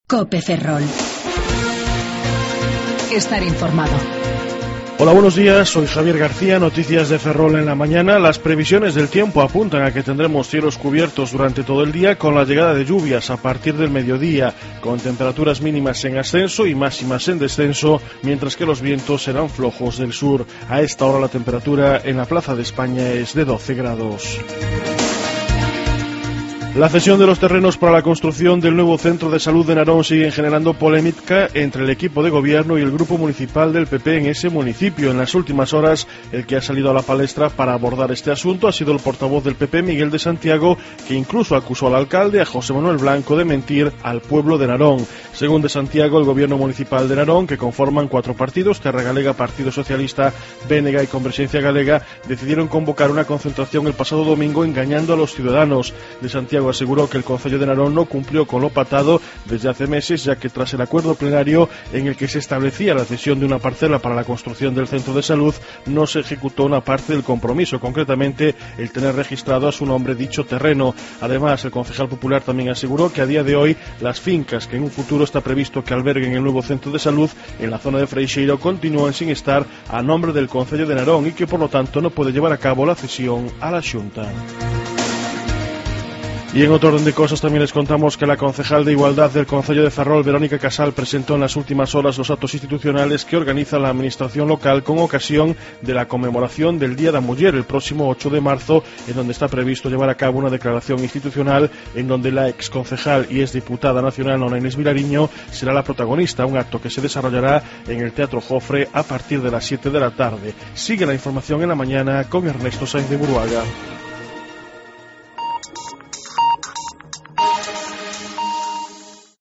07:28 Informativo La Mañana